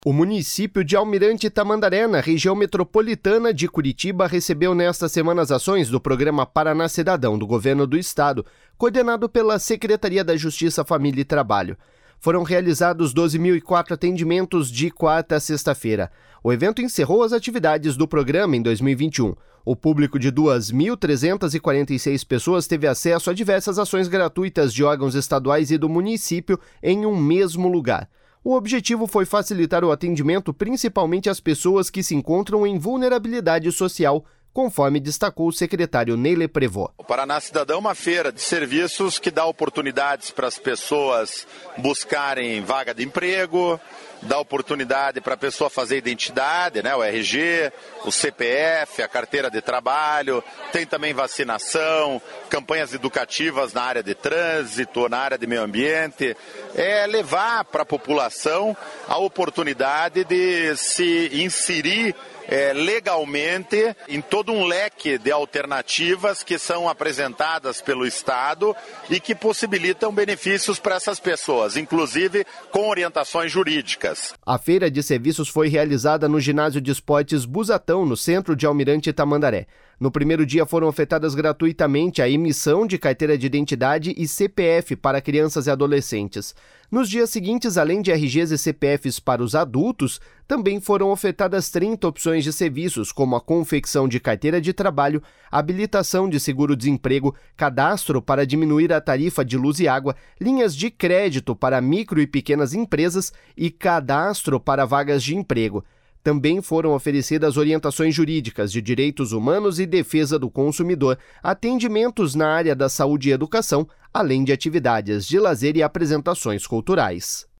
O objetivo foi facilitar o atendimento principalmente às pessoas que se encontram em vulnerabilidade social, conforme destacou o secretário Ney Leprevost.// SONORA NEY LEPREVOST.//